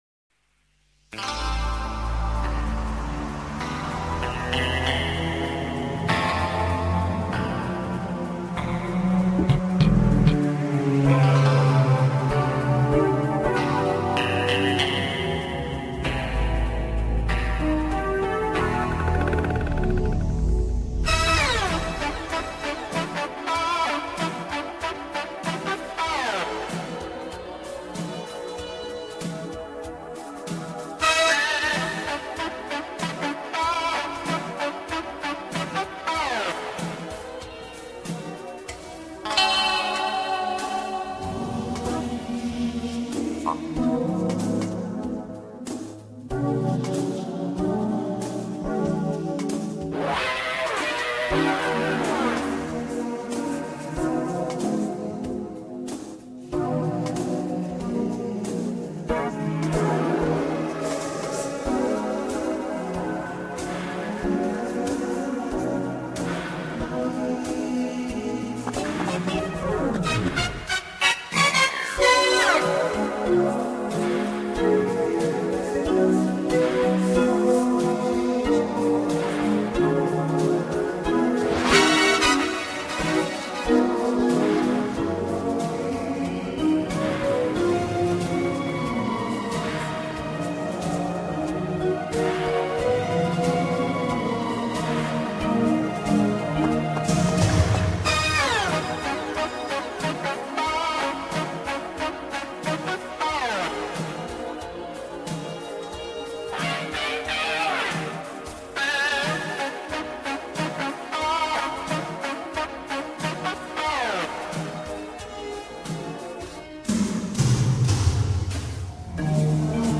Медленный минус, среднее качество